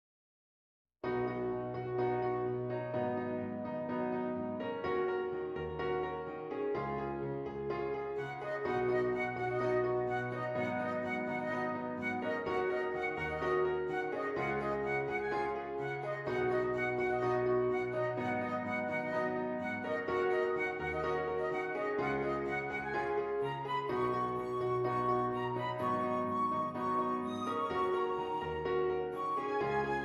Flute Solo with Piano Accompaniment
Does Not Contain Lyrics
B Major
Moderate Pop